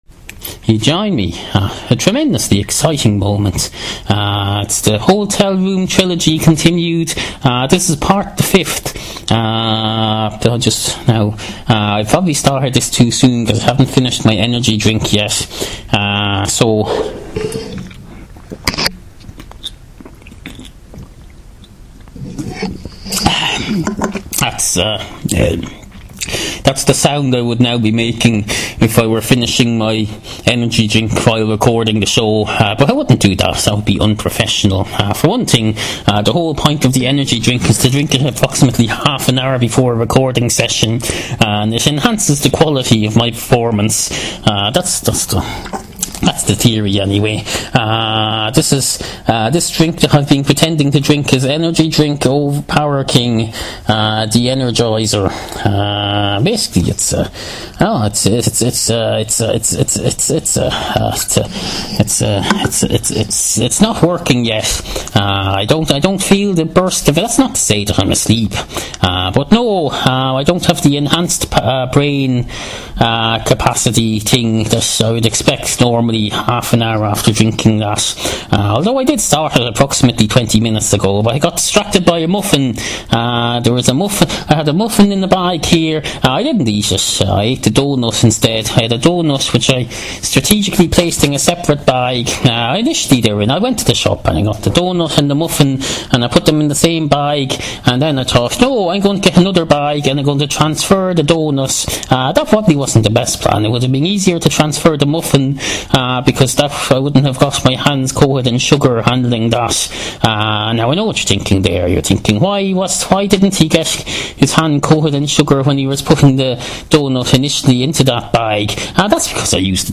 New listeners are reeuested to start with later episodes, like the 500s or, even better, the 800s. he conclusion of a five part trilogy, recorded in the sanctuary of a hotel bedroom and bathroom, somewhere in central Ireland.